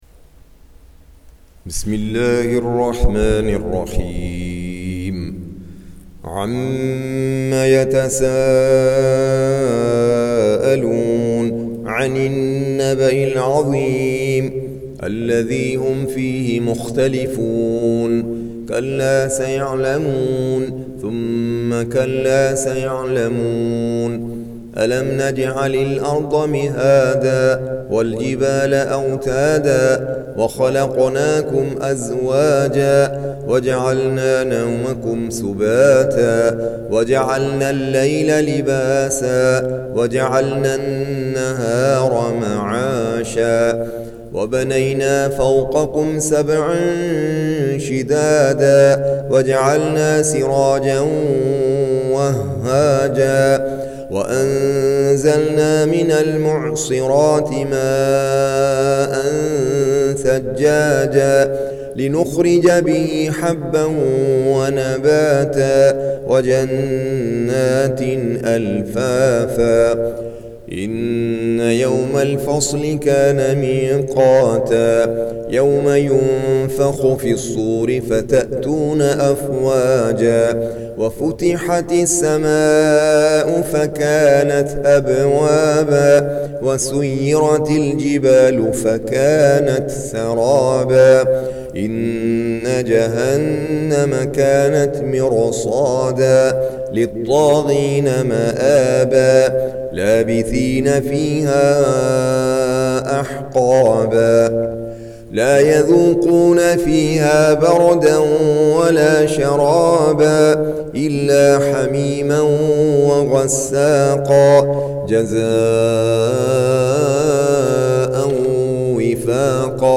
Surah Repeating تكرار السورة Download Surah حمّل السورة Reciting Murattalah Audio for 78. Surah An-Naba' سورة النبأ N.B *Surah Includes Al-Basmalah Reciters Sequents تتابع التلاوات Reciters Repeats تكرار التلاوات